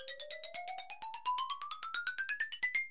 Category: Message Ringtones